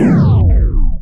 SCIFI_Down_07_mono.wav